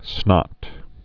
(snŏt)